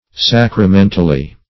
sacramentally - definition of sacramentally - synonyms, pronunciation, spelling from Free Dictionary
sacramentally - definition of sacramentally - synonyms, pronunciation, spelling from Free Dictionary Search Result for " sacramentally" : The Collaborative International Dictionary of English v.0.48: Sacramentally \Sac`ra*men"tal*ly\, adv.